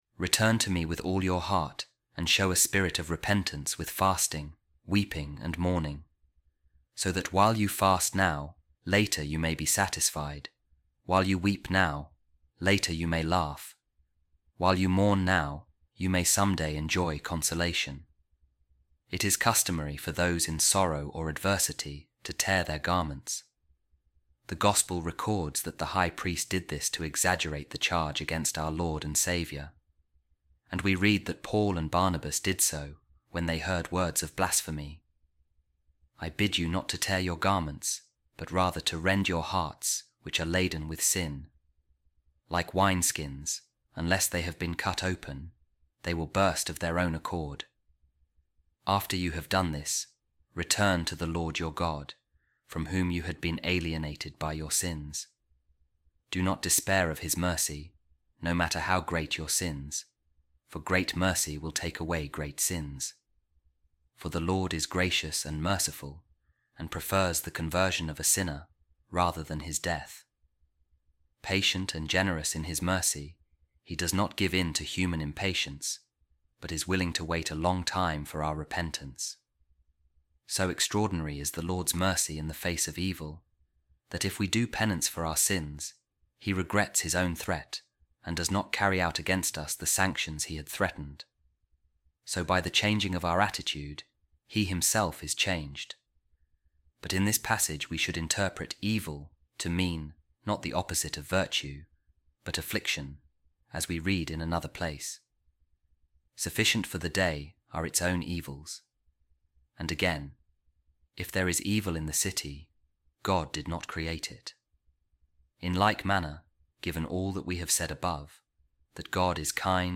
A Reading From The Commentary Of Saint Jerome On The Book Of Joel | Return To Me With All Your Heart